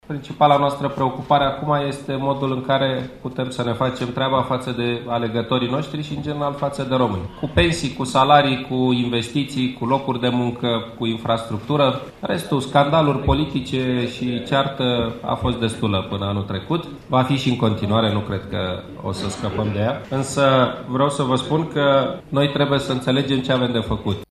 La începutul şedinţei, premierul Victor Ponta a declarat: